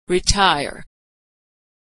Voiceless /t/
When /t/ is stressed, it has a puff of air.
When /t/ is not stressed, it does not have a puff of air.